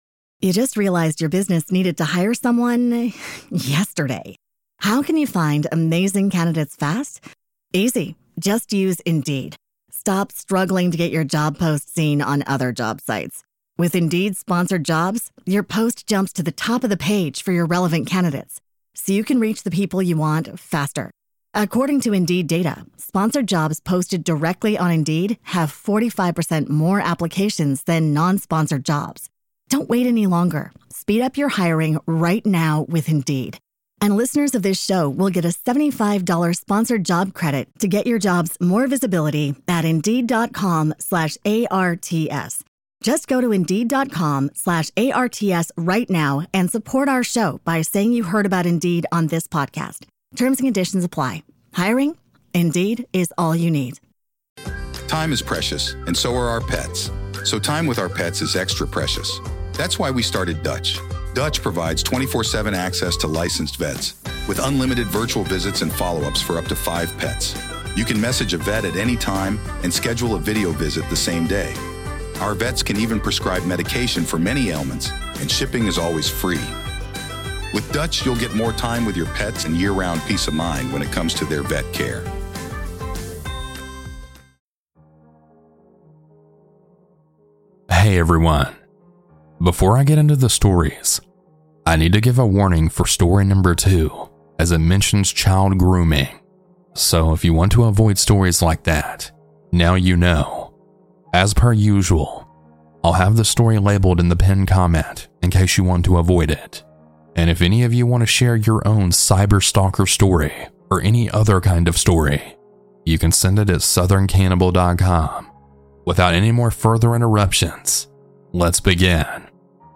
Huge Thanks to these talented folks for their creepy music!